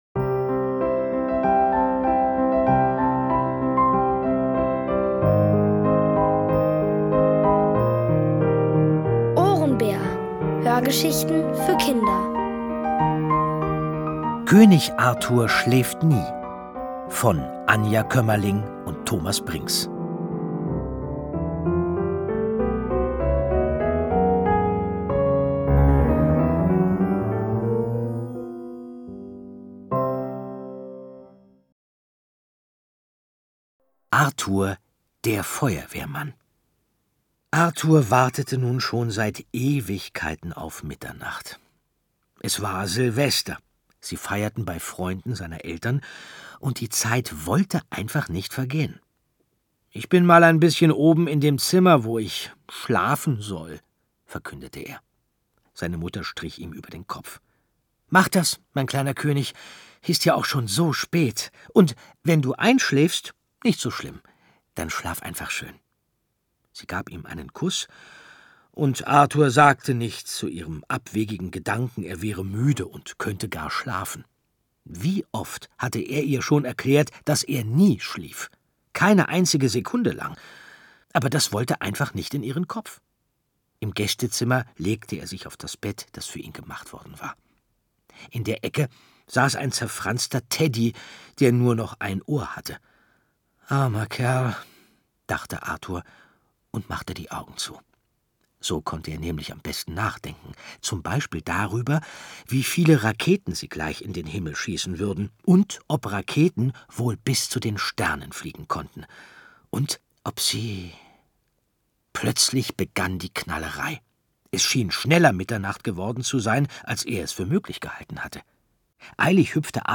Von Autoren extra für die Reihe geschrieben und von bekannten Schauspielern gelesen.
Es liest: Andreas Fröhlich.